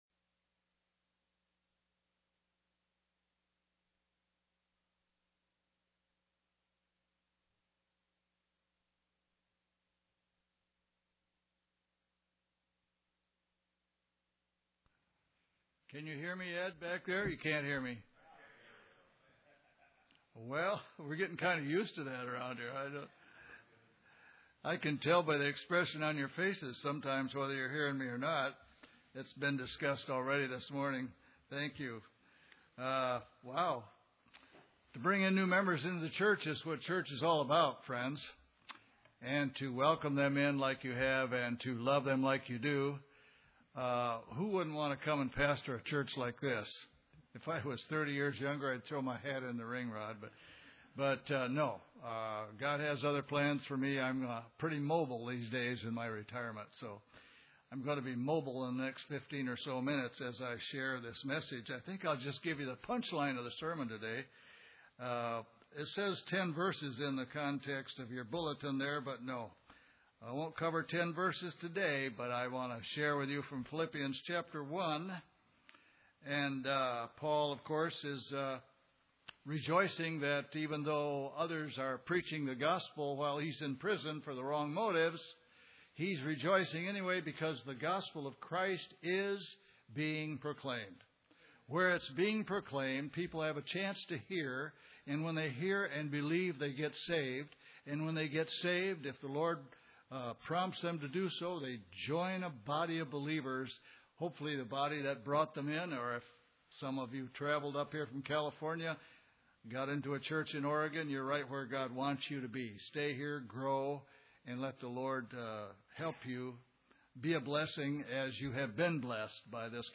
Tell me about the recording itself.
Passage: Philippians 1:20-30 Service Type: Sunday Service